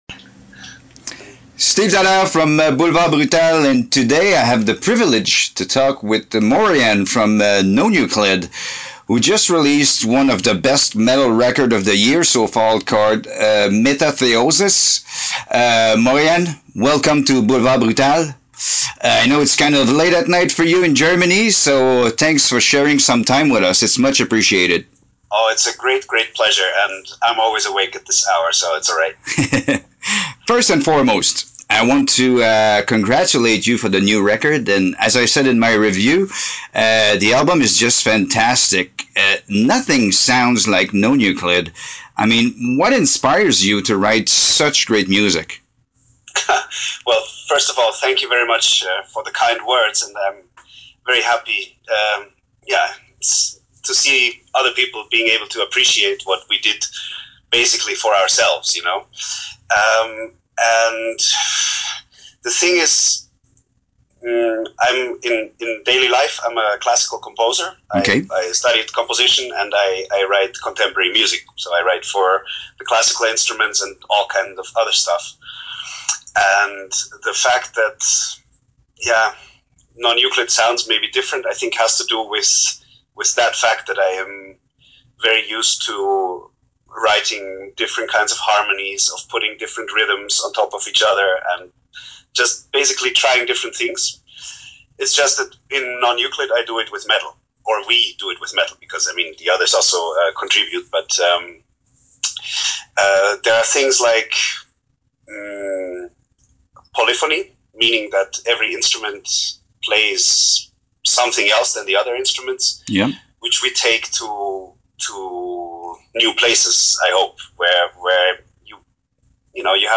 Je n’ai rien coupé au montage parce que je trouvais ça très sympathique.